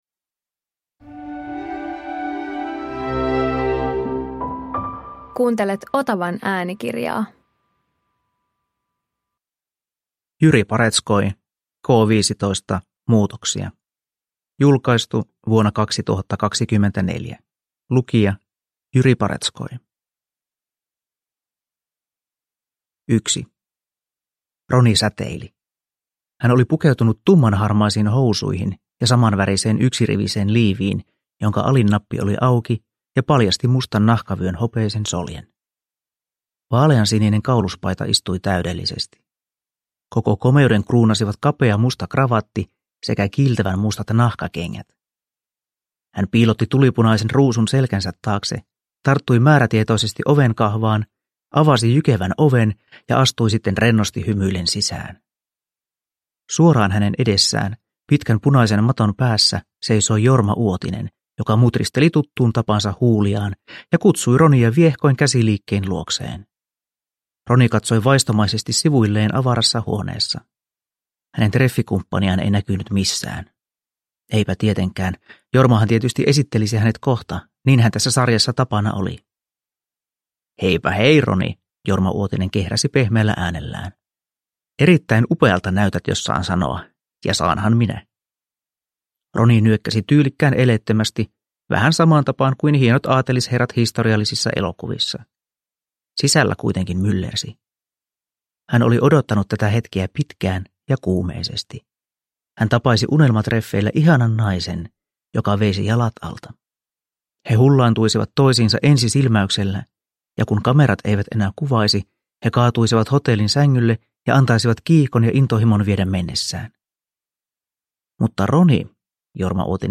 K15 - Muutoksia – Ljudbok